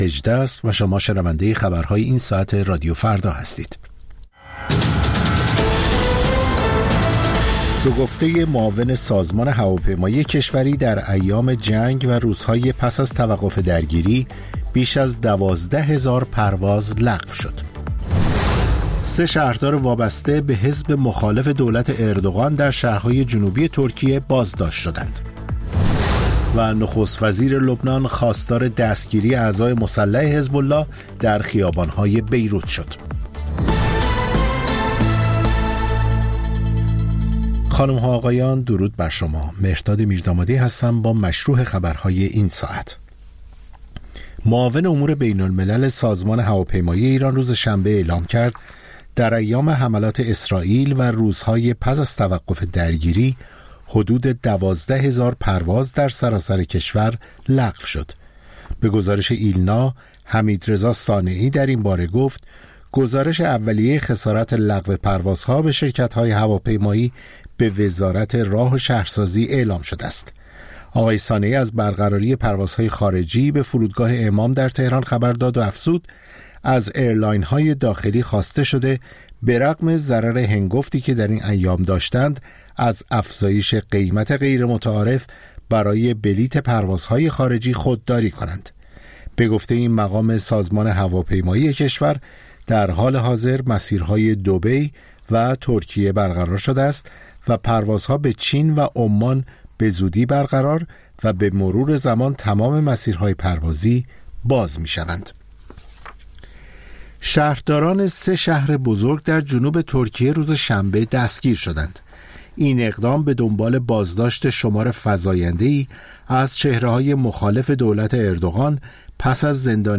سرخط خبرها ۱۸:۰۰